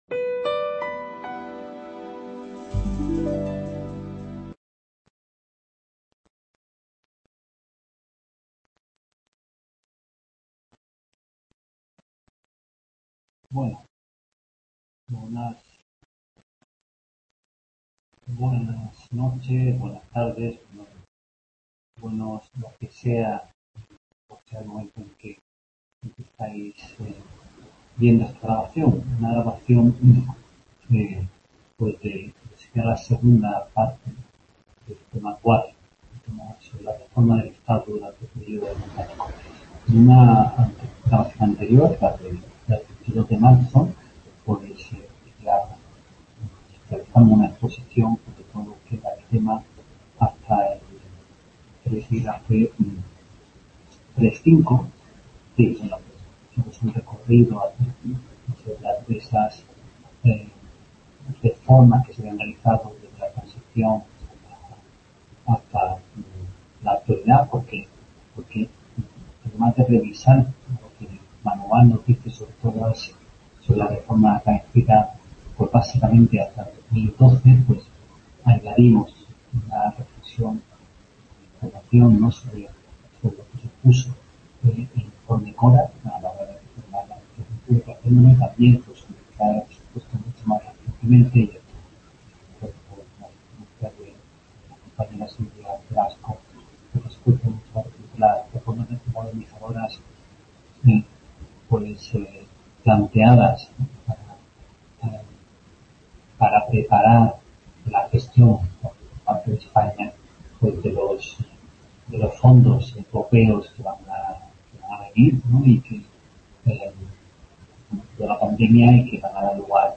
En esta tutoría se completa la exposición del tema 4 (epígrafes 4 y 5) y se comentan preguntas del tema que cayeron en exámenes de cursos anteriores.